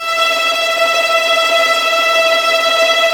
Index of /90_sSampleCDs/Roland LCDP13 String Sections/STR_Violins Trem/STR_Vls Tremolo